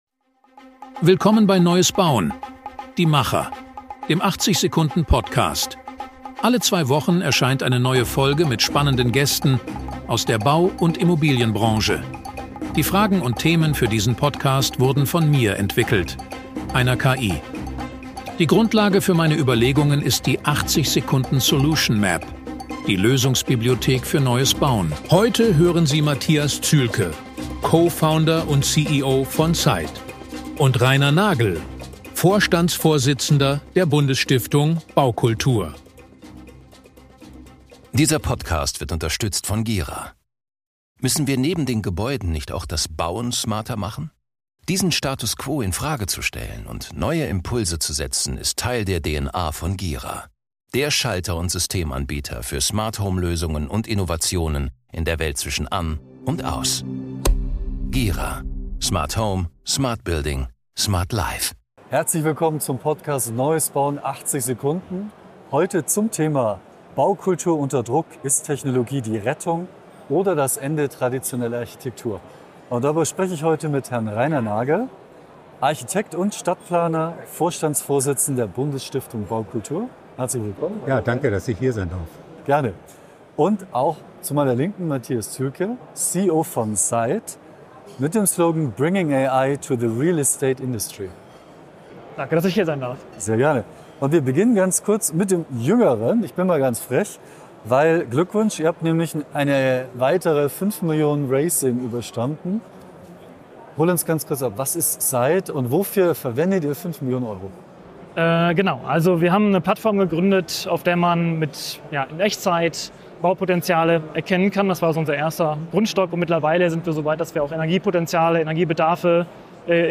Wie verändern KI, Energieeffizienz und Sanierungspotenziale die Architektur von morgen – und was bleibt vom menschlichen Gestaltungswillen? Ein Gespräch über Verantwortung, Ästhetik und die Zukunft des Bauens.